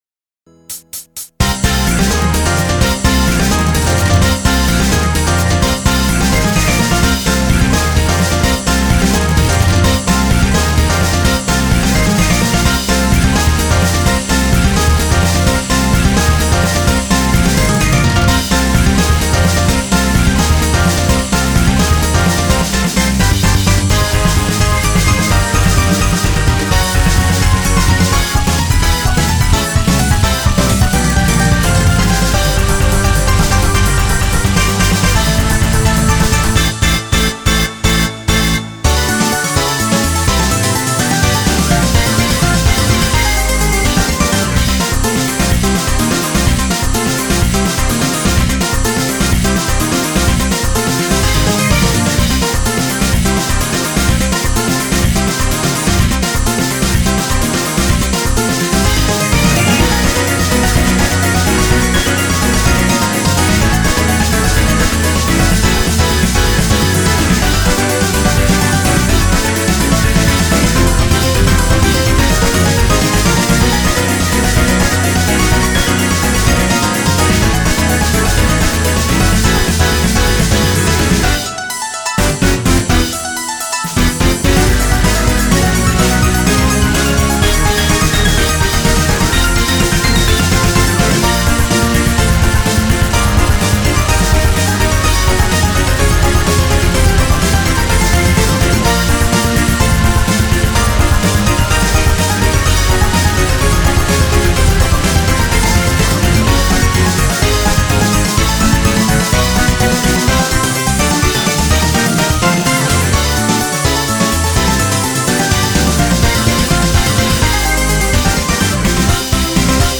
BPM252-256
Audio QualityCut From Video